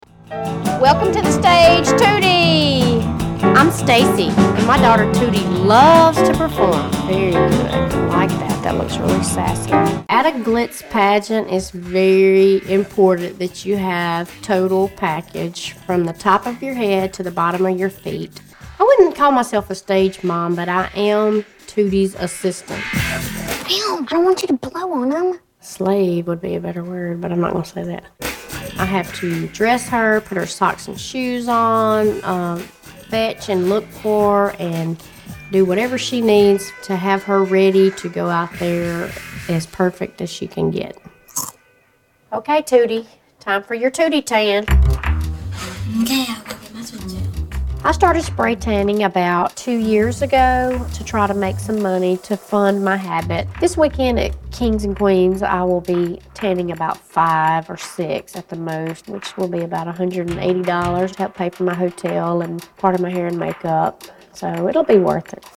Tags: Toddlers and Tiaras Toddlers and Tiaras clips Toddlers and Tiaras Moms Toddlers and Tiaras interviews Toddlers and Tiaras sound clips